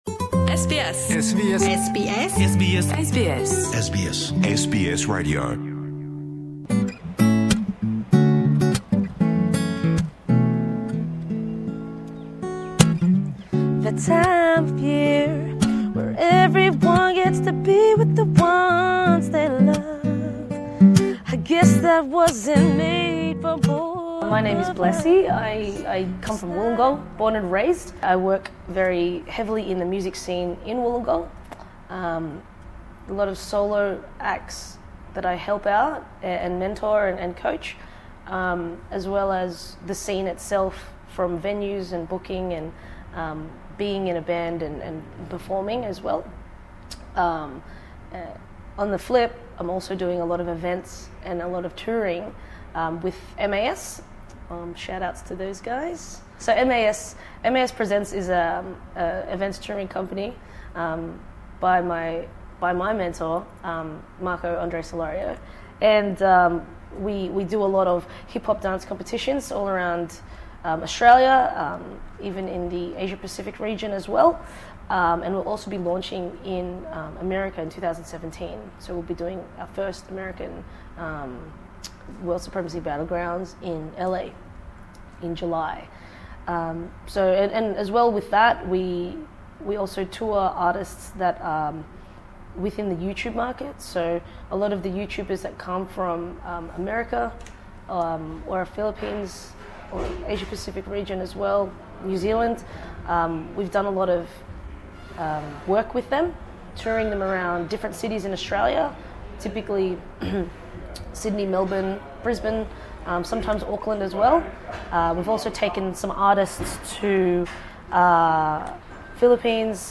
In the interview, she speaks about her start in the world of music, the diversity of music in Wollongong, and the young talents associated with Cyrus who won the X Factor in Australia in 2015.